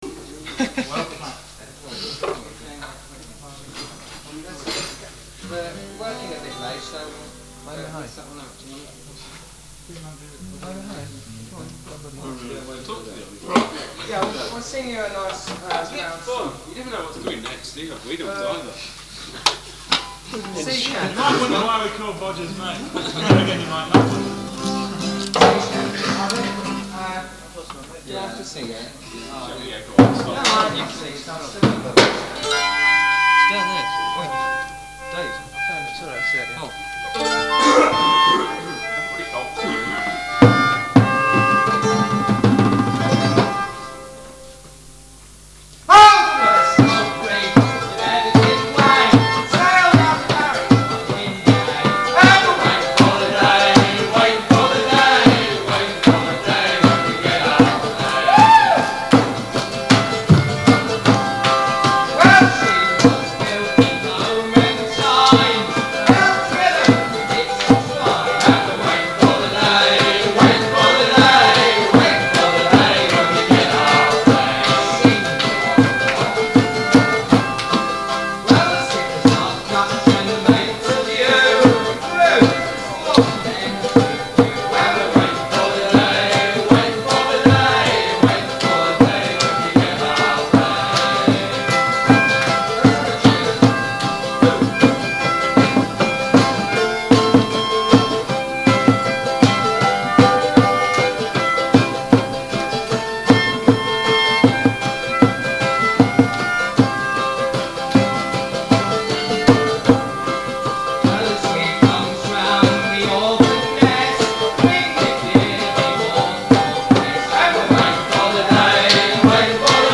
Recorded at Harvester's Folk Club, City of Leicester College of Edication, Scraptoft, Leicester in 1976 when the line up of Bodgers Mate was:-
Mandolin, Bouzouki, viola, Vocals
Pipes, Whistle, guitar, Vocals
Fiddle, Vocals